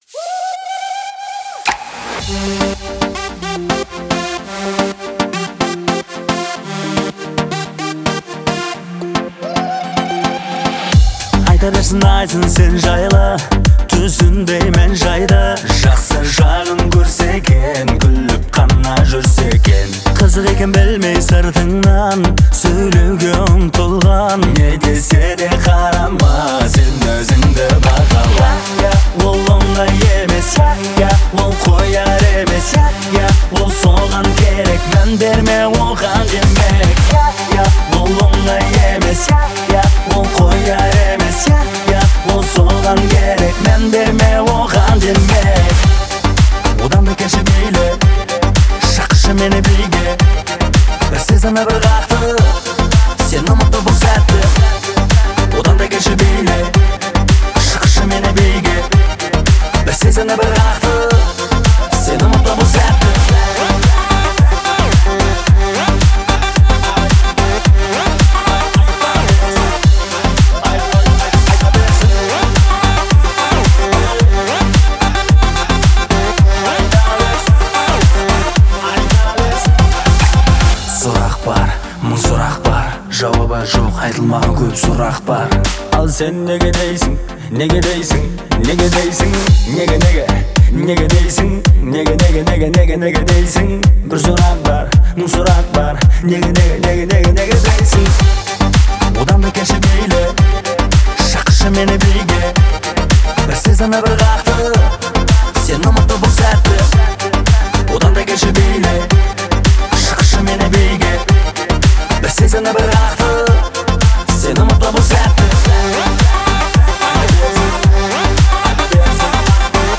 это трек в жанре казахского поп-фолка